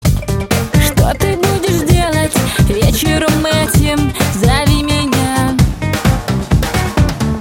• Качество: 128, Stereo
женский голос
голосовые